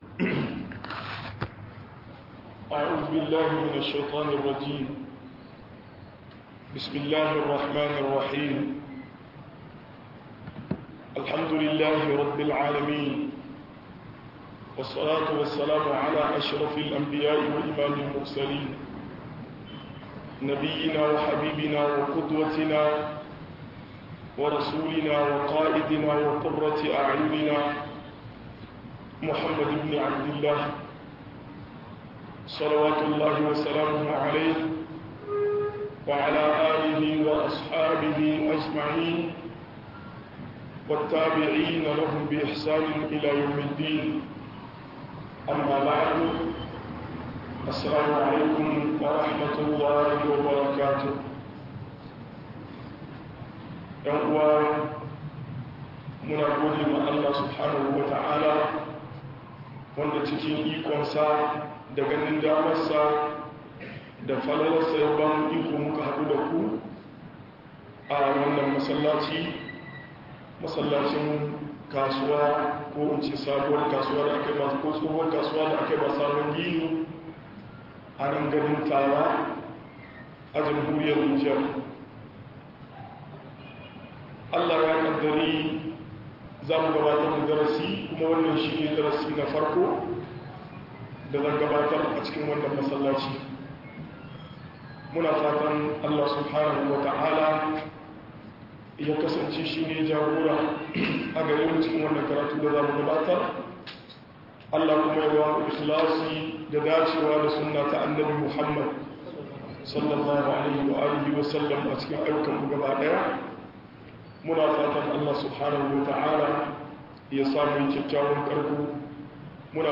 In babu imani dangantaka ba tada amfani - MUHADARA